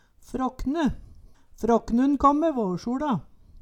Høyr på uttala Ordklasse: Substantiv hokjønn Kategori: Kropp, helse, slekt (mennesket) Attende til søk